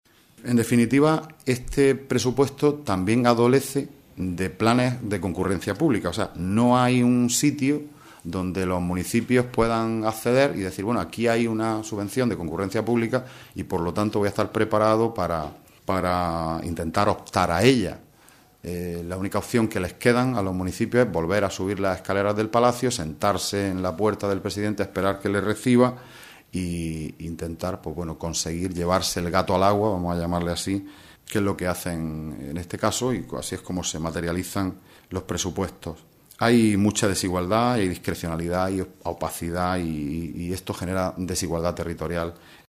Rueda de prensa que ha ofrecido el Grupo Socialista en la Diputación Provincial de Almería